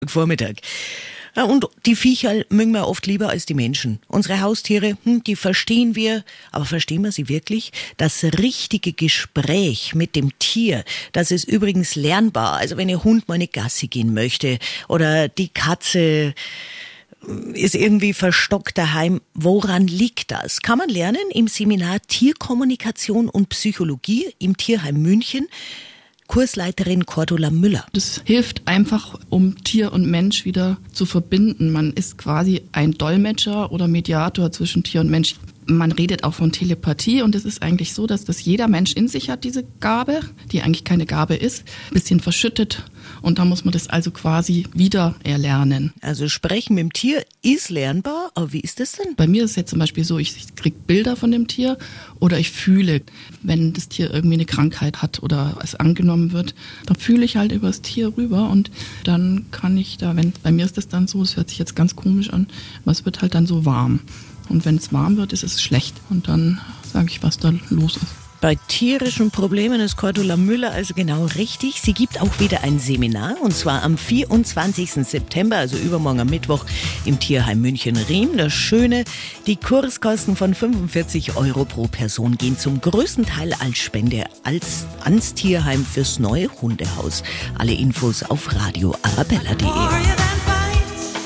audio_interview.mp3